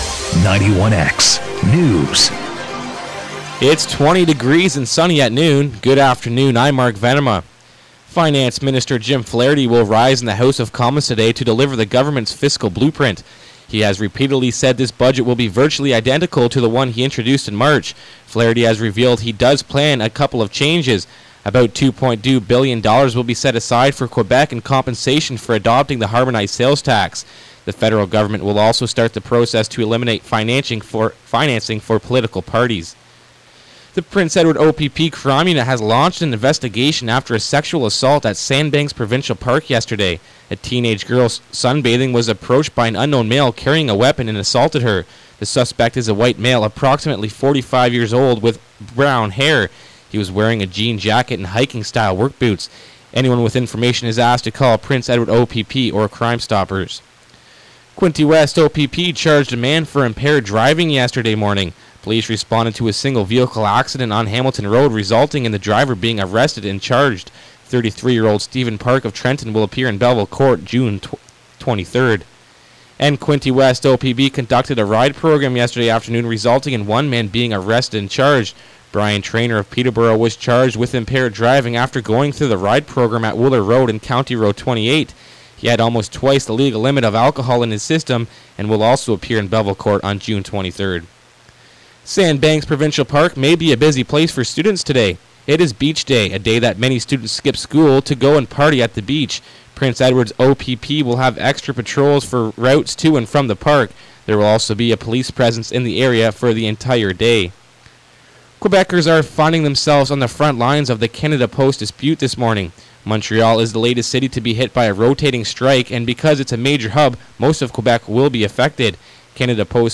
91x News, June 6, 2011, 12pm